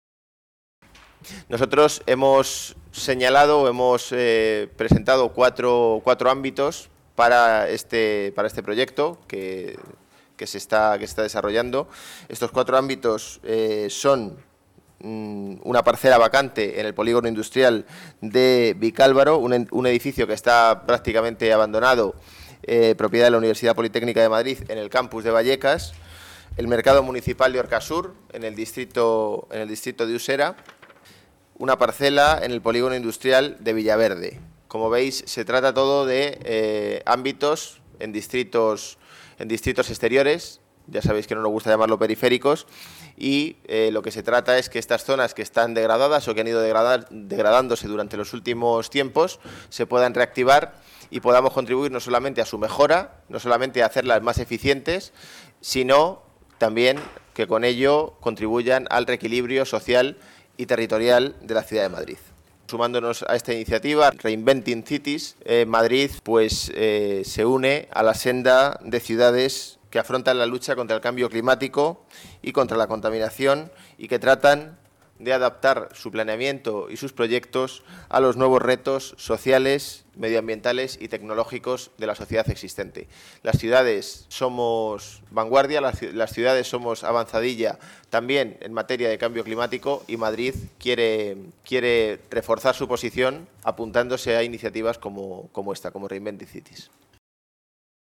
Nueva ventana:José Manuel Calvo, delegado de Desarrollo Urbano Sostenible, explicando el proyecto Reinventing Cities